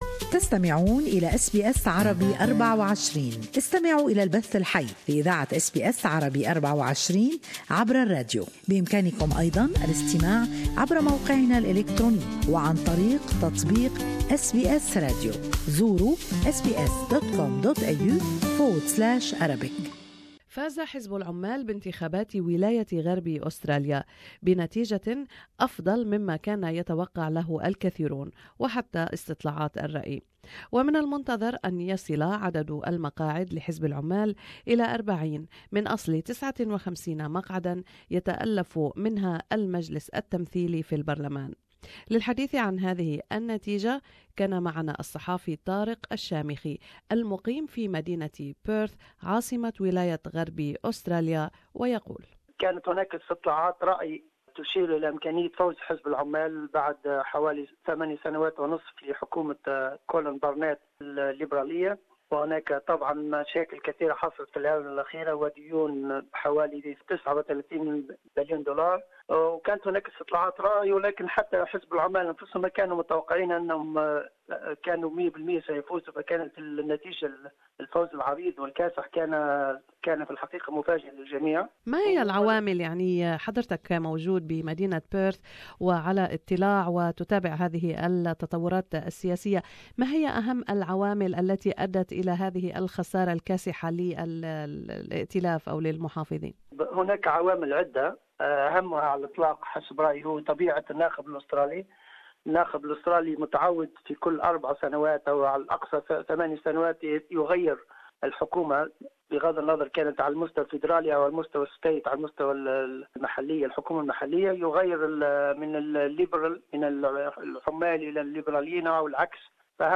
فاز حزب العمال بانتخابات ولاية غرب أستراليا بنتيجة أفضل مما توقها له الكثيرون واستطلاعات الرأي. عن هذه النتيجة تحدثنا إلى الصحافي والباحث في العلوم السياسية